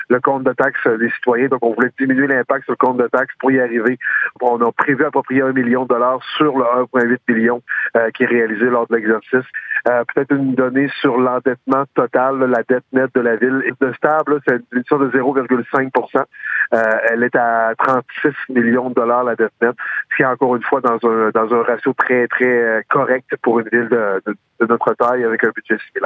Le maire, Daniel Côté, explique qu’un million du montant a été utilisé pour équilibrer le budget.